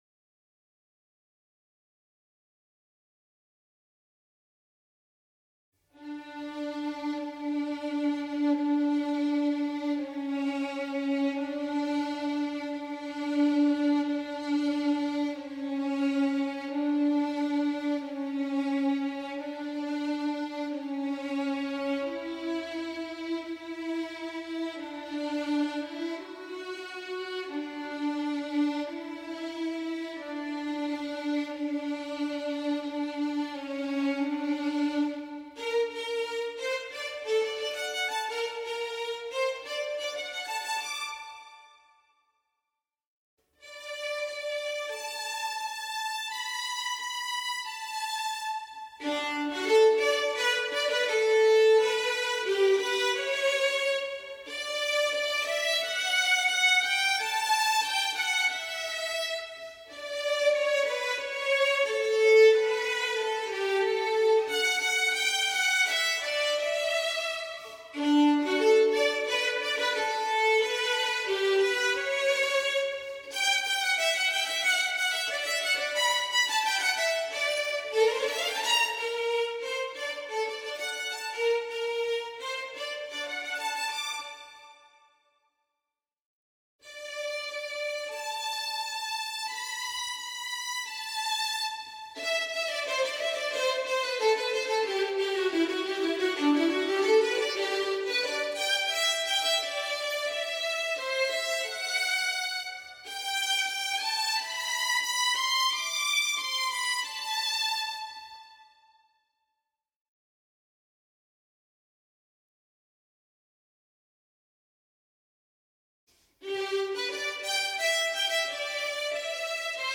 Music for Violin 1
07-Violin-1.mp3